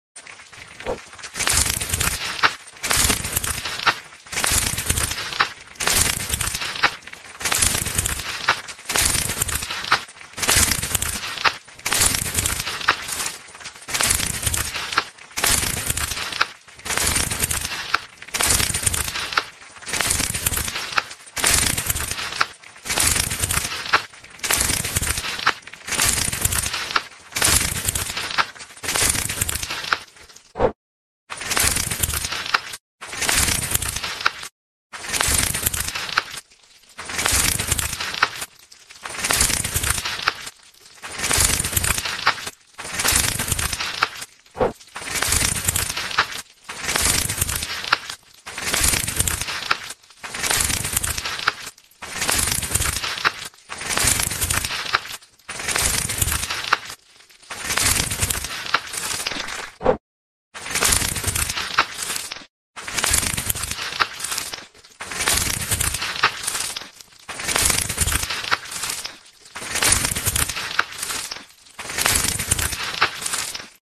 Treatment heads ASMR Part sound effects free download